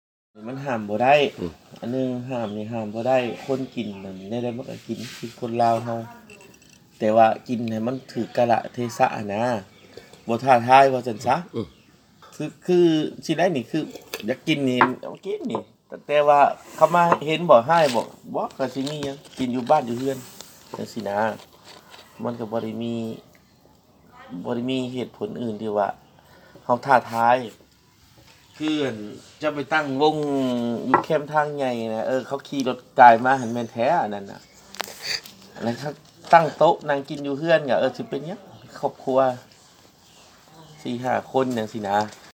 ສຽງສໍາພາດ ປະຊາຊົນ ເຂດເມືອງສີໂຄດຕະບອງ.